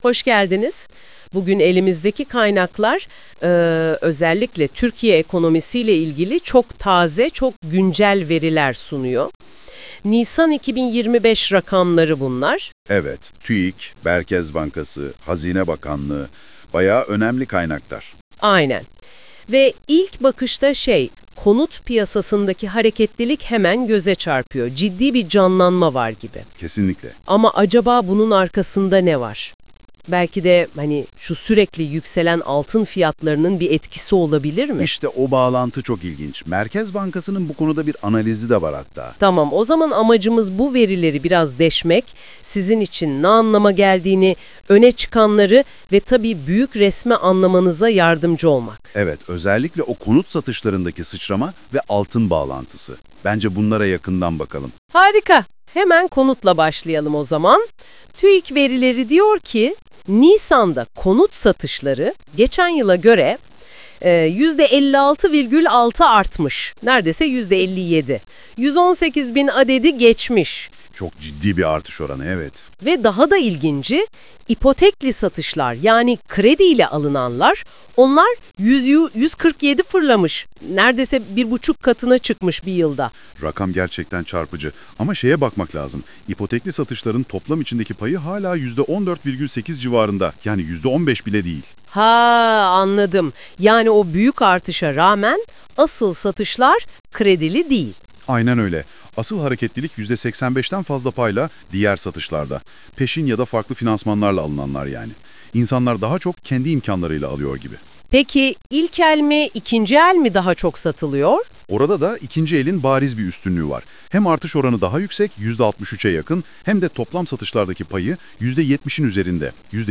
Jeopolitik gelişmeler, finansal haberler ve ekonomik verileri içeren TEMA GRUP HAFTALIK FİNANS VE EKONOMİ BÜLTENİ’ne buradan ulaşabilirsiniz. Yapay zeka AI ile yapılan sesli versiyonu